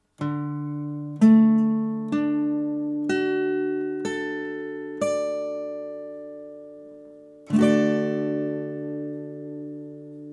D-Dur (Barré, E-Saite)
D-Dur-Akkord, Barre E-Saite, Gitarre
D-Dur-Barre-E.mp3